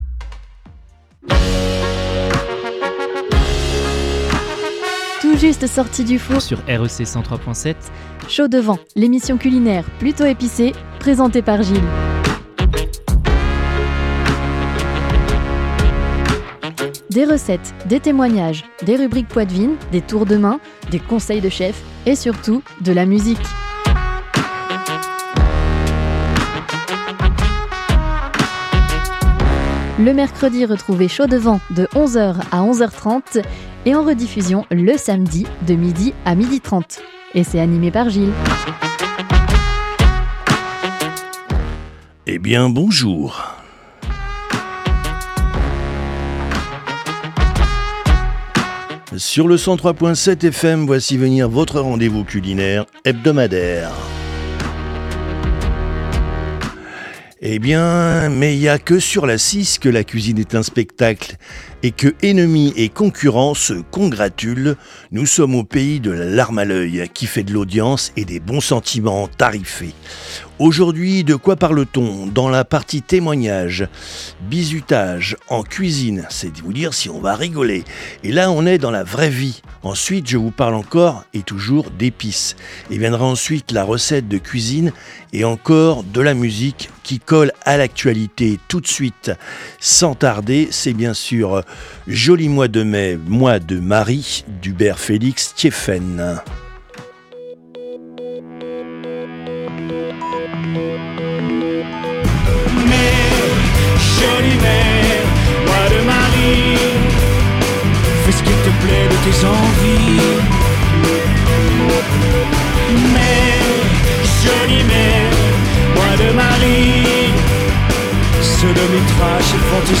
avec anecdotes, témoignages , rubriques , recettes , conseils de chef et musiques !